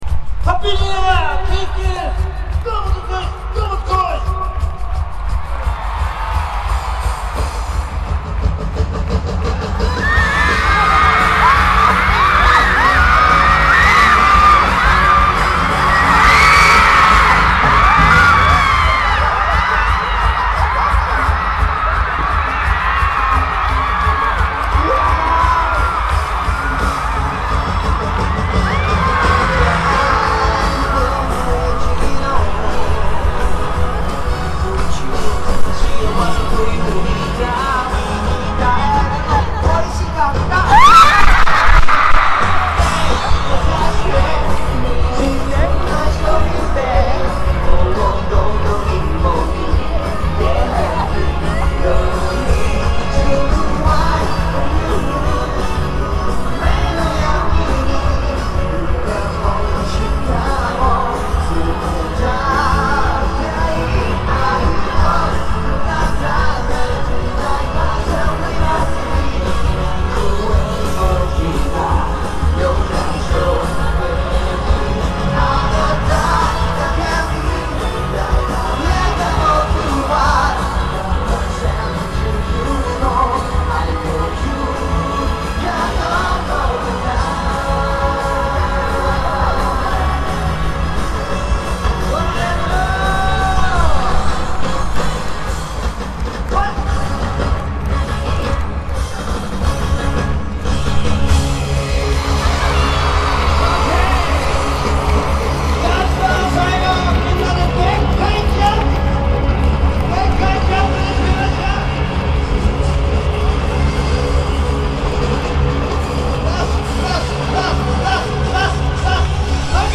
※ 그리고 고음에서는 튀는 부분이 있는데 그건 다 팬들 목소리 때문ㅋㅋㅋ
팬들 목소리에 묻혀서 잘 안들리지만, 귀 기울여 들어보시면 분명히 지릅니다.
관객들: 모잇까이!! 모잇까이!! 모잇까이!!! 한 번 더!! 한 번 더!!! 한 번 더!!!!!